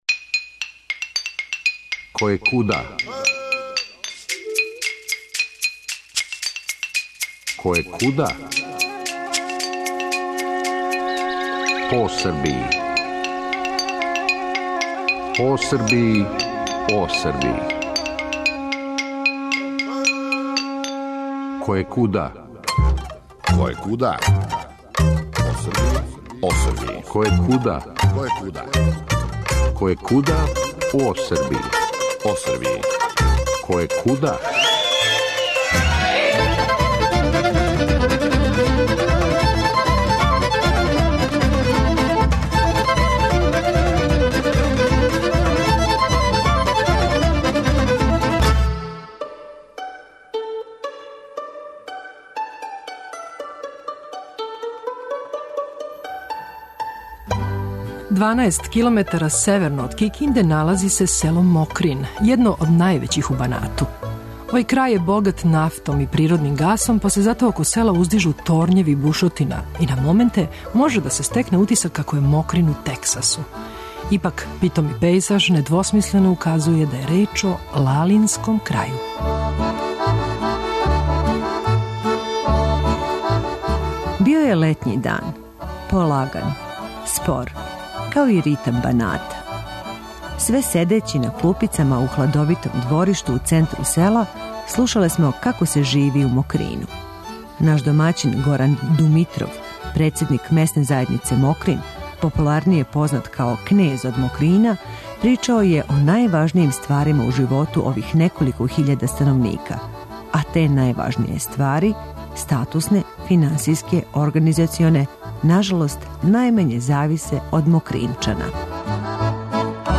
Једног летњег дана, седећи на клупама у "дебелој" хладовини, у центру села, слушале смо причу о животу људи, о проблемима и предностима које препознају, али, и о томе по чему су надалеко чувени - а то су, свакако, гускови, ускршња јаја, резерват природе који је станиште беле дропље, и - најзад - велики песник Мика Антић, рођени Мокринчанин.